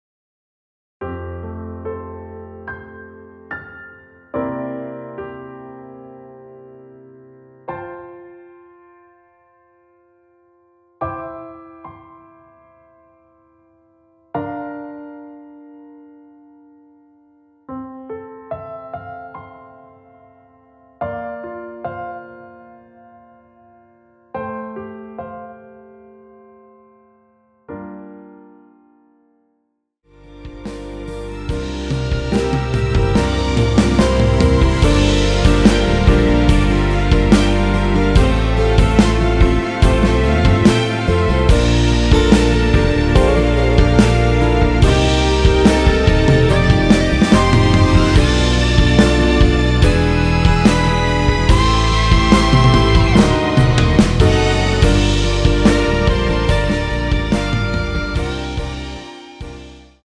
원키에서 (+5)올린 MR 입니다.
노래가 바로 시작 하는 곡이라 전주 만들어 놓았습니다.
앞부분30초, 뒷부분30초씩 편집해서 올려 드리고 있습니다.